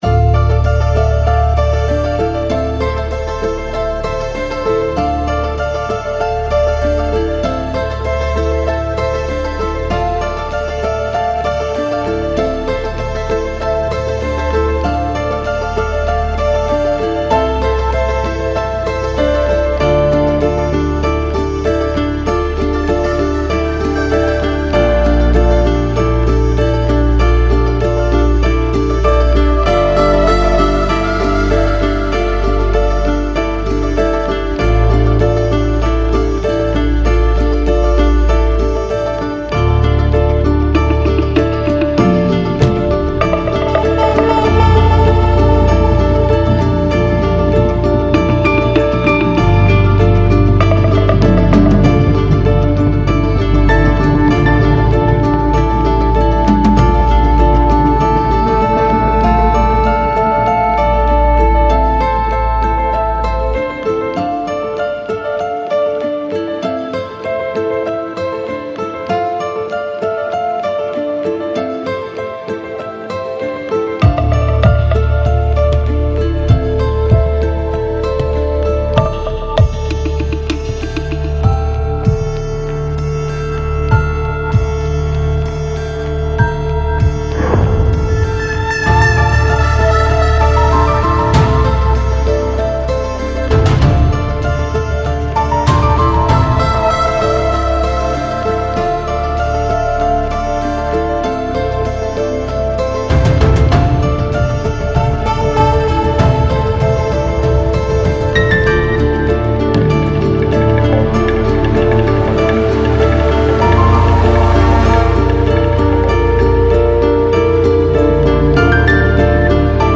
Largo [40-50] melancolie - ensemble instruments - - -